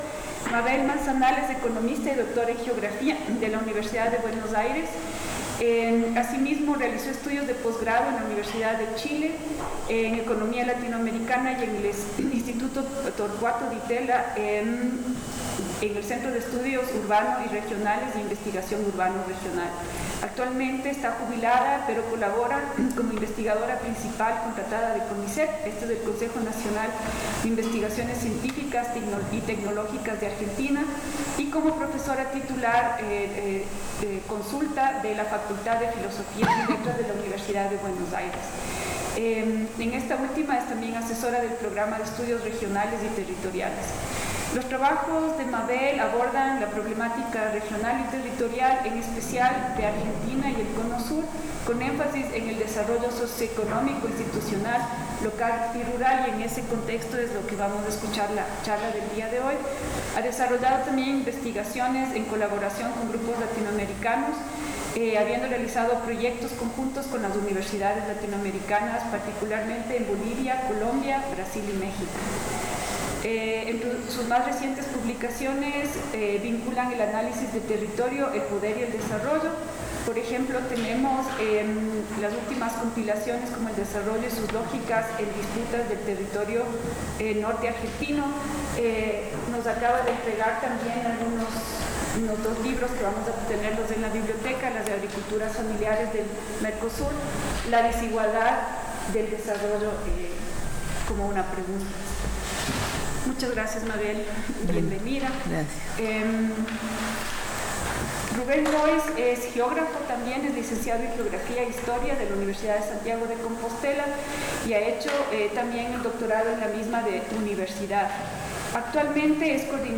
Conferencia Desarrollo territorial en América Latina: el caso del Cono Sur